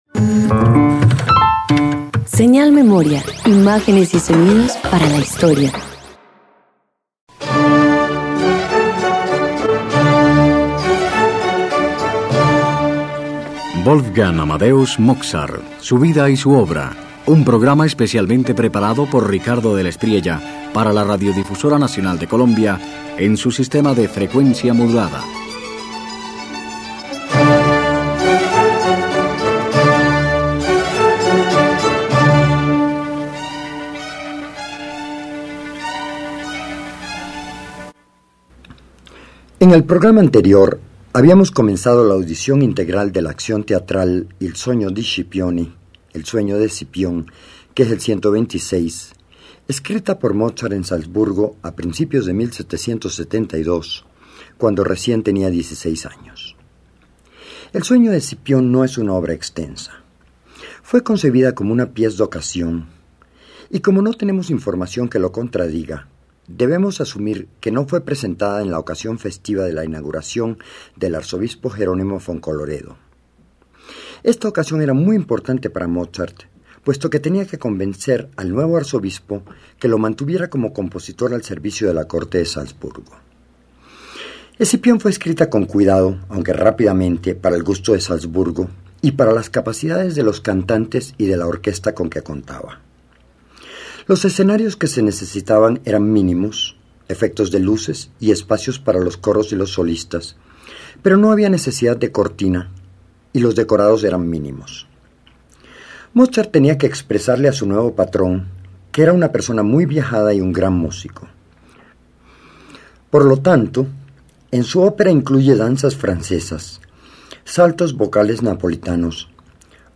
Mozart despliega su genio juvenil con sofisticación: mezcla danzas francesas y saltos vocales napolitanos, exalta seis voces y revela, a través de Fortuna y Constancia, el dilema moral de Escipión entre la gloria terrenal y la virtud eterna.